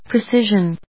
音節pre・ci・sion 発音記号・読み方
/prɪsíʒən(米国英語), pri:ˈsɪʒʌn(英国英語)/